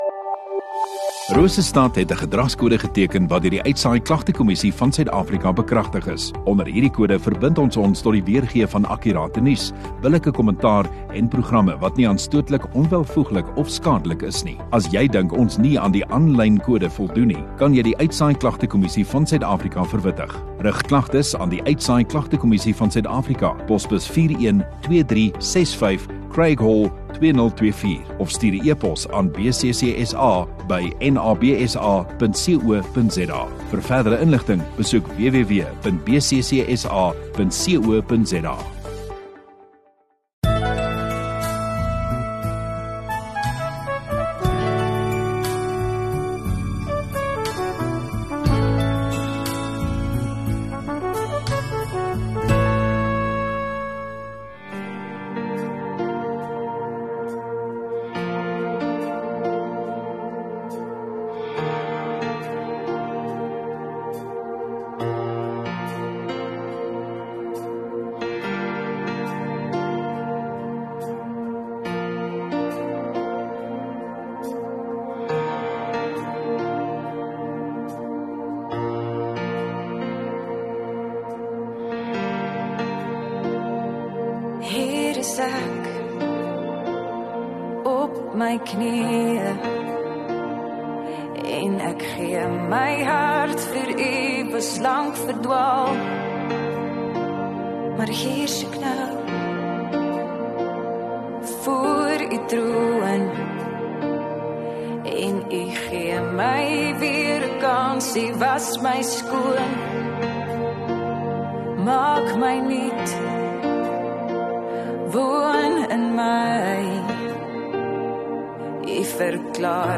View Promo Continue Radio Rosestad Install Rosestad Godsdiens 12 Apr Sondagoggend Erediens